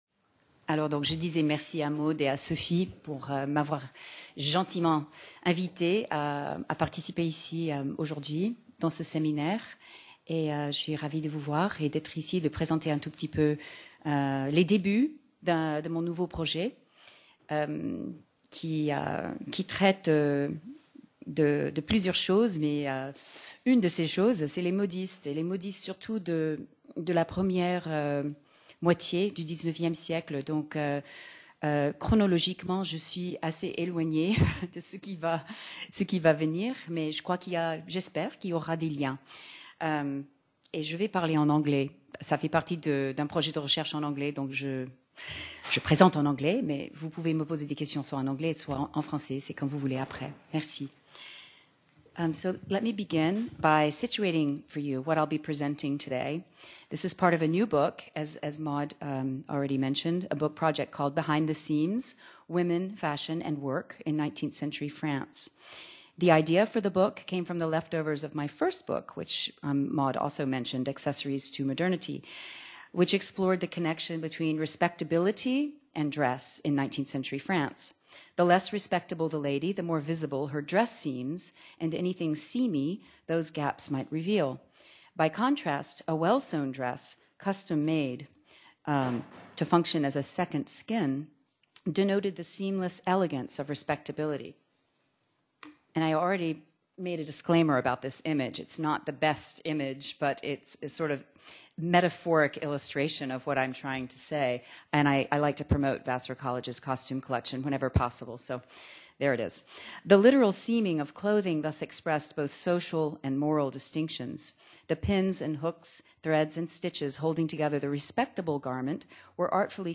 Séminaire histoire de mode. Les Femmes Qui Font la Mode : Directrices, Couturières, Petites Mains.